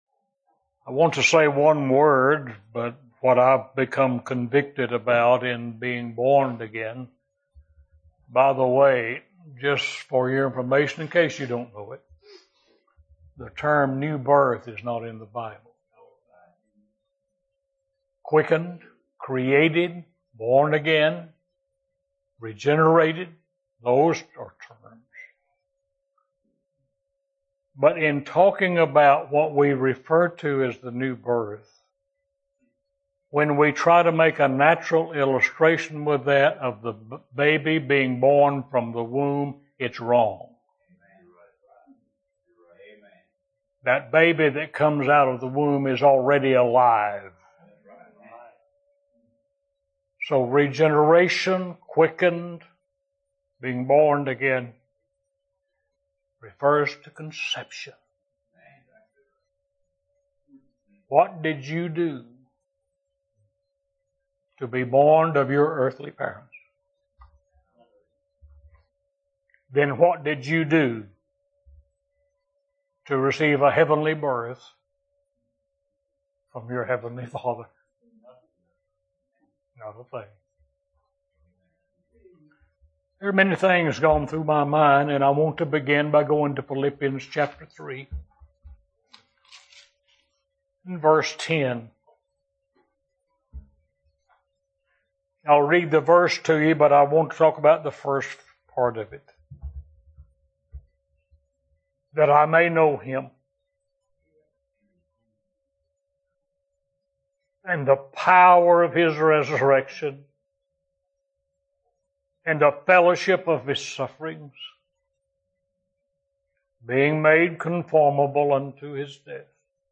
First Primitive Baptist Ass’n of OK – 2015 July 10, Friday Morning